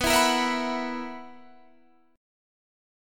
BM7sus4 chord